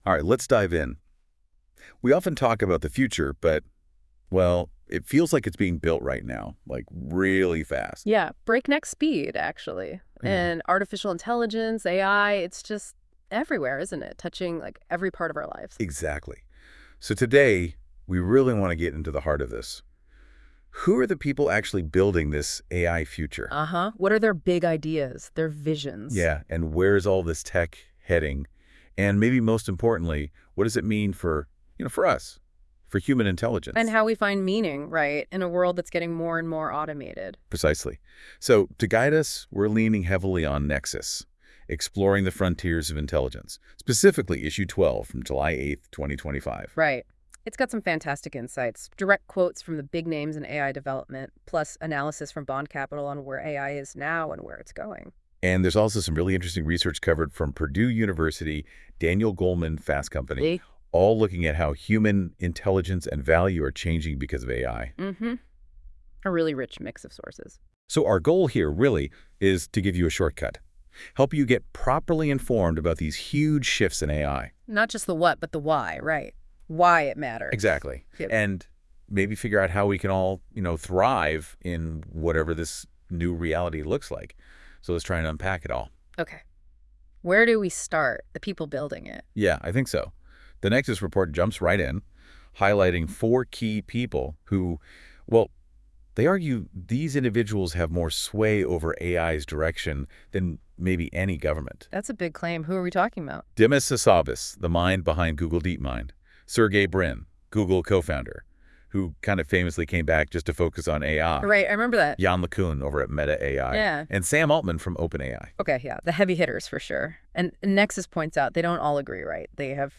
Nexus Deep Dive is an AI-generated conversation in podcast style where the hosts talk about the content of each issue of Nexus: Exploring the Frontiers of Intelligence .